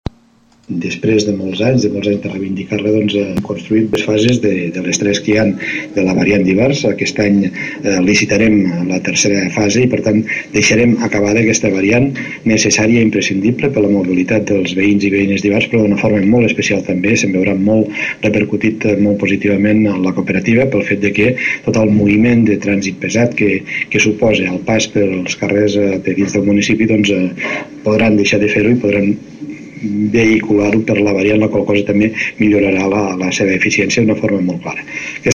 El president de la Diputació de Lleida, Joan Reñé, ho ha anunciat a Ivars d’Urgell, en el transcurs de la cloenda de l’Assemblea General de la Cooperativa.